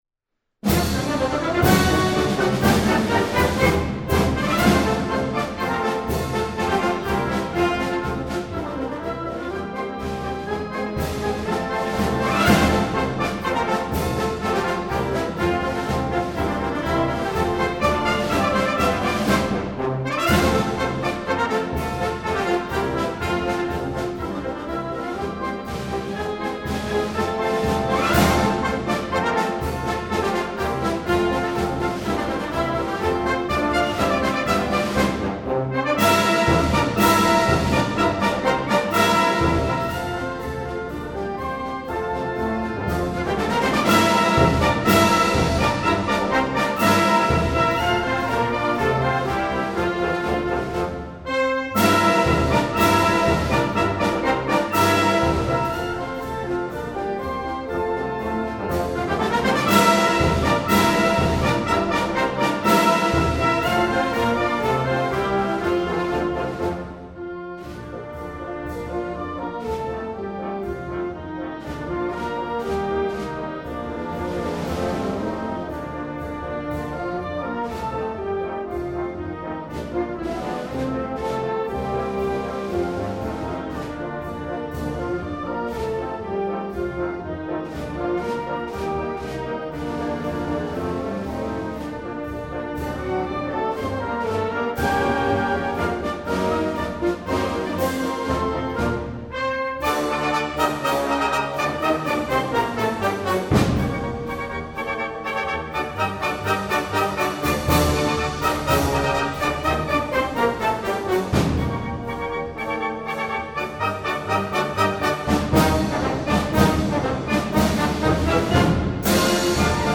Instrumentation: concert band